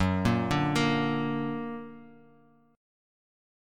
Listen to Gb+ strummed